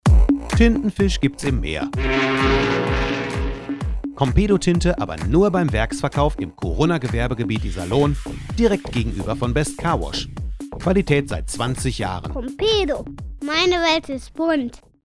Produktion von Werbejingles